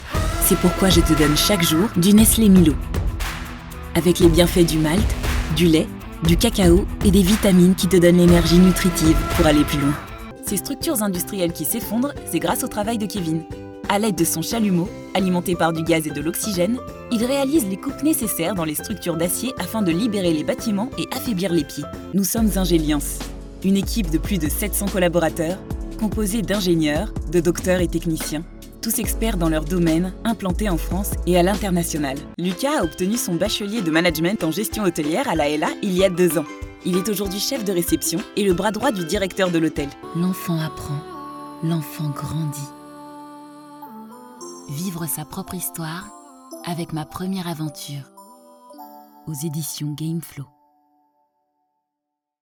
Voix off
voix-femme